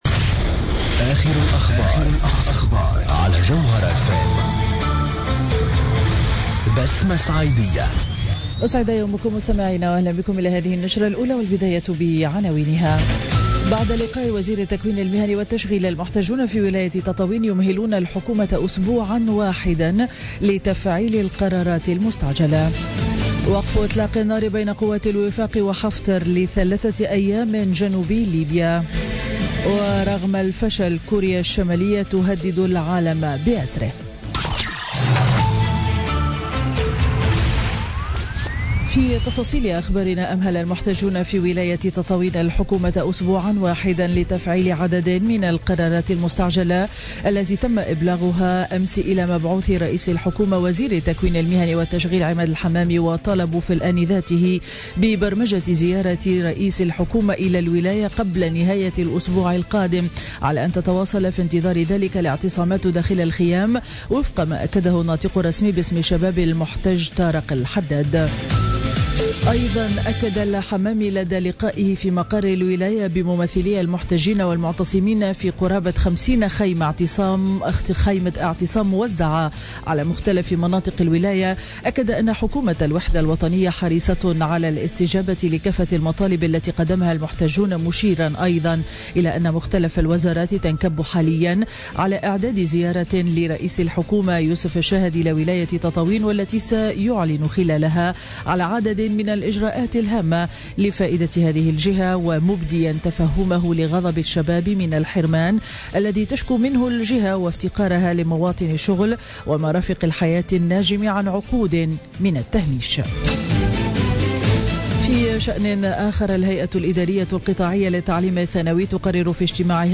نشرة أخبار السابعة صباحا ليوم الأحد 16 أفريل 2017